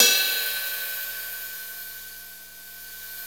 CYM XRIDE 2C.wav